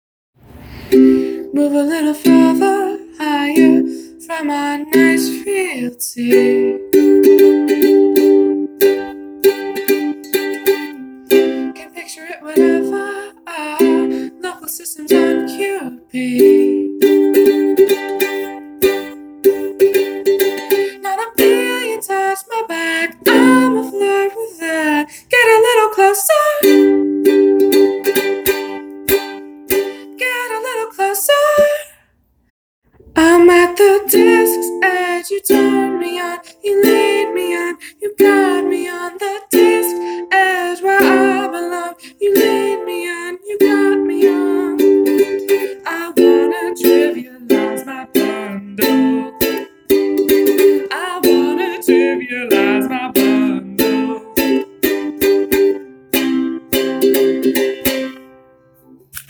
Ukulele Songs from the TamagaWHAT Seminar